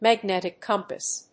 magnétic cómpass